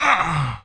dfury_grunt4.wav